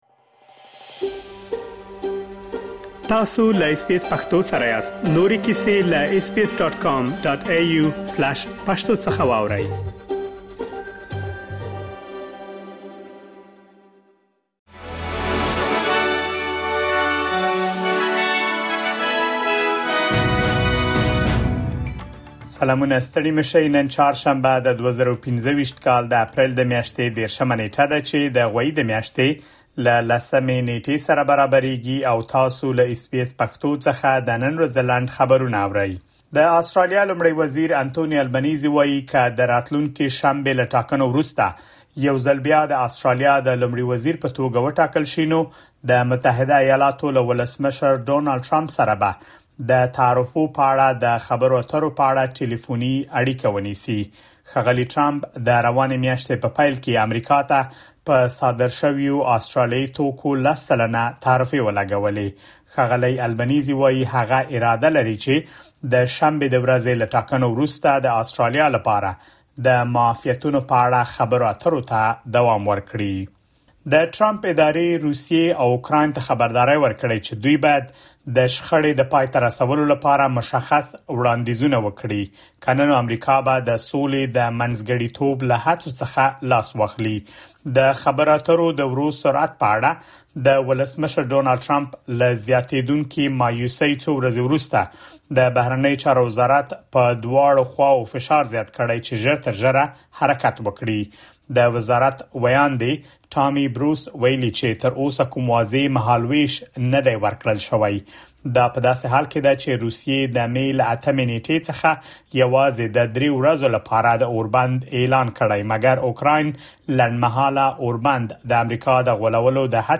د اس بي اس پښتو د نن ورځې لنډ خبرونه | ۳۰ اپریل ۲۰۲۵
د اس بي اس پښتو د نن ورځې لنډ خبرونه دلته واورئ.